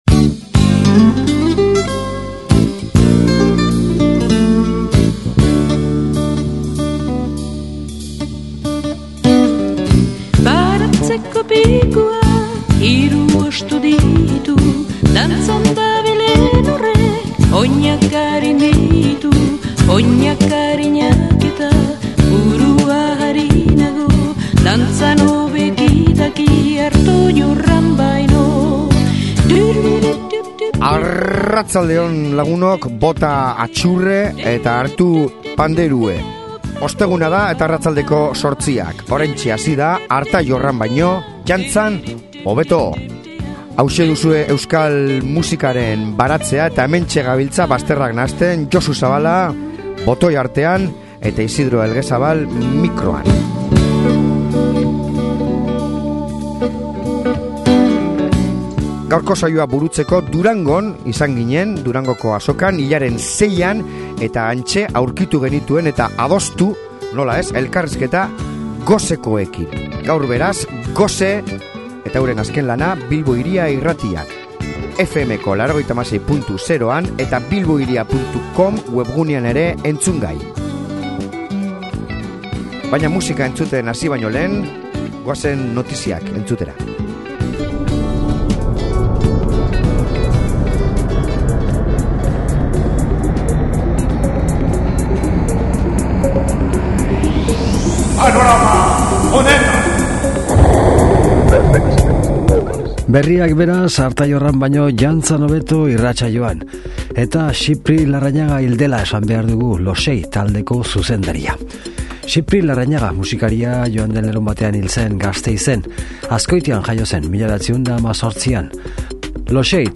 Durangoko Azokan izan ginen hilaren 6an;